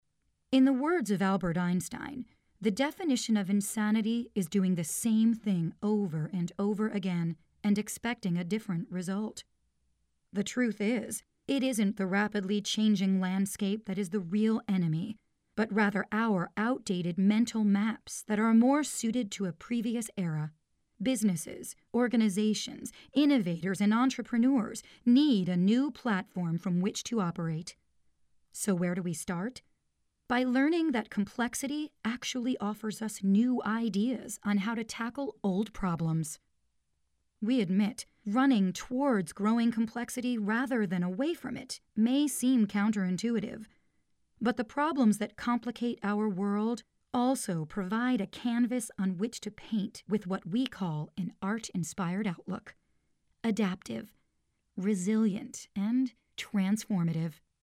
voice over sultry conversational comic funny velvet smooth professional actor phone messages
mid-atlantic
Sprechprobe: Industrie (Muttersprache):